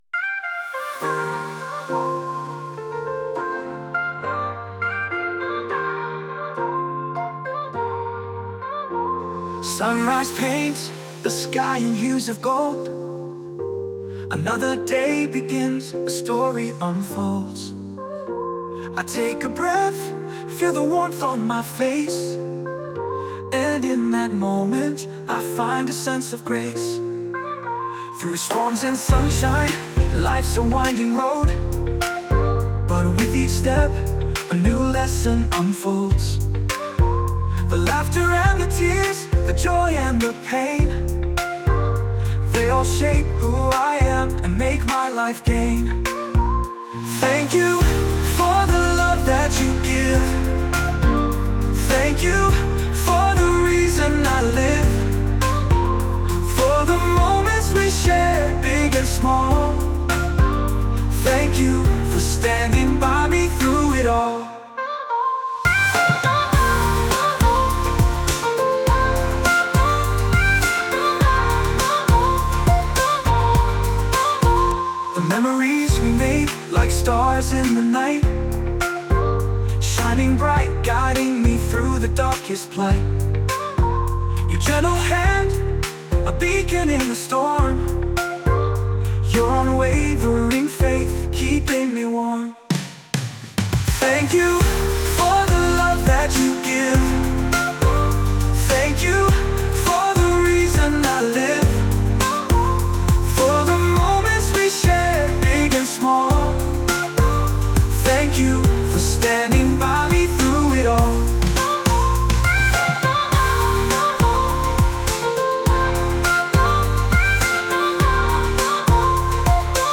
歌詞ありフリー音源。